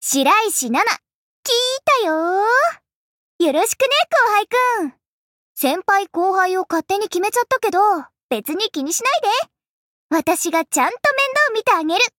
白石奈奈自我介绍语音.mp3.ogg